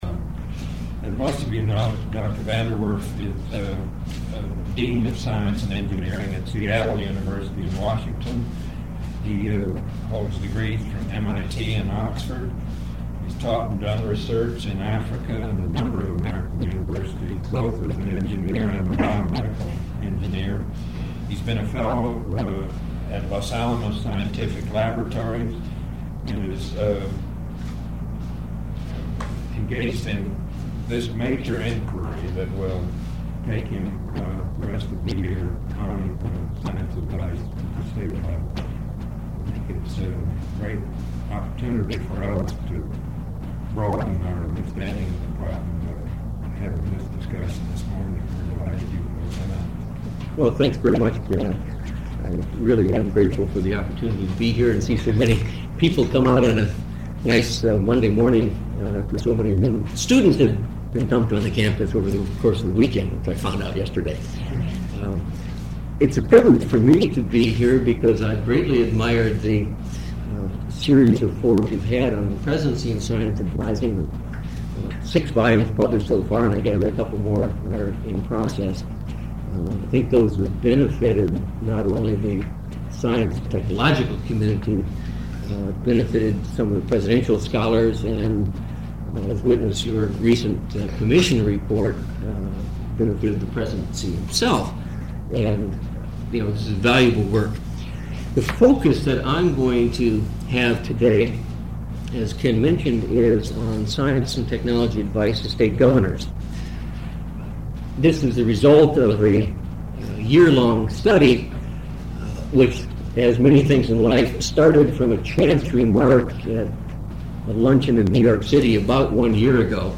The audio recording is a little bit hard to hear until about a minute into the presentation.